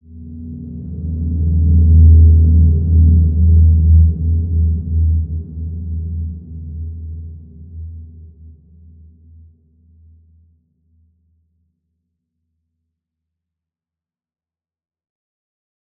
Large-Space-E2-p.wav